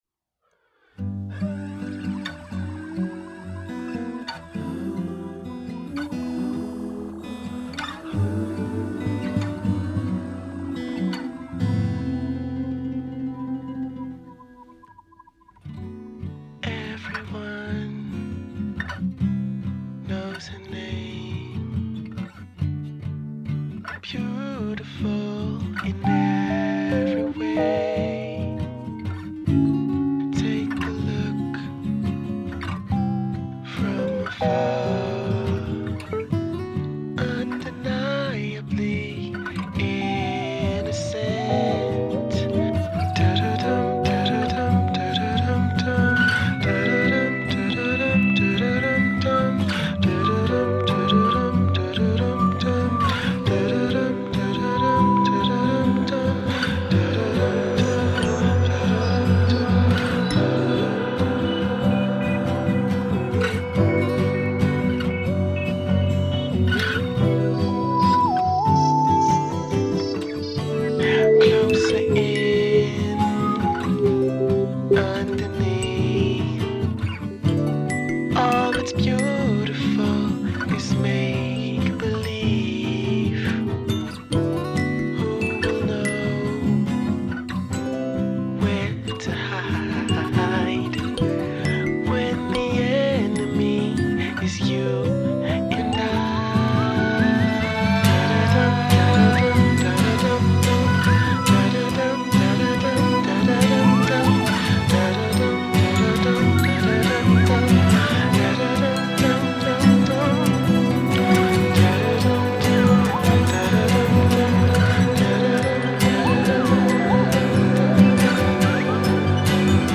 2. The switch to 7/4 time as the song hits 3 minutes.